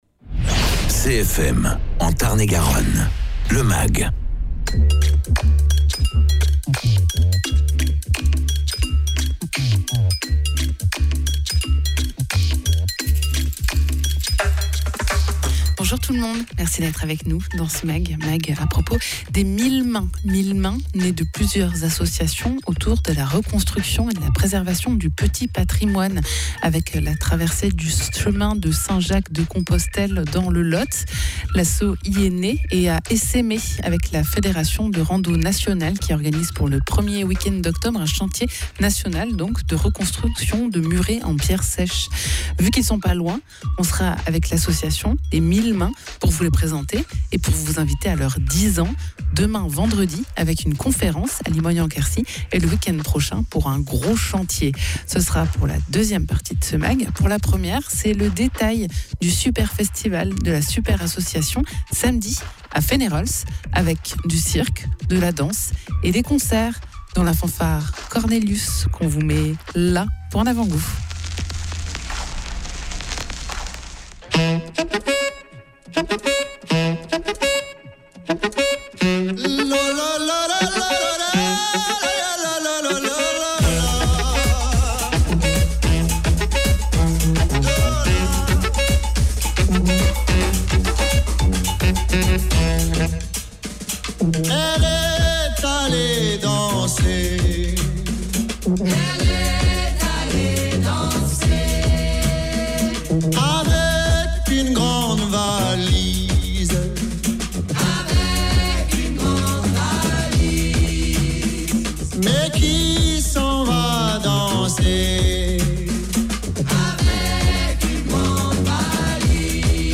La super asso organise son superfestival samedi à Feneyrols : du cirque de la danse et des concerts au parc des thermes dès 18h30. Egalement dans ce mag, une rencontre avec 1000 mains : une association lotoise qui oeuvre pour la restauration du petit patrimoine autour du chemin de Compostelle : elle fête ses 10 ans avec une conférence vendredi soir à Limogne en Quercy.